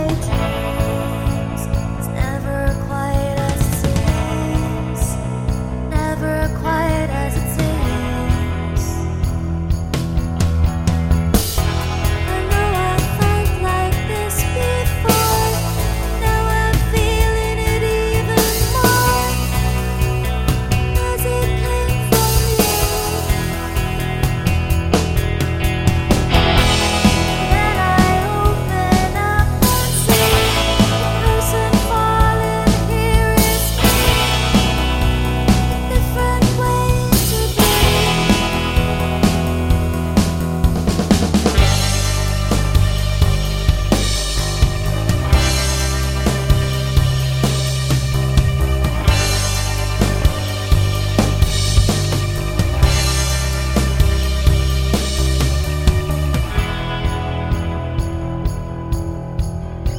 no Backing Vocals Irish 4:31 Buy £1.50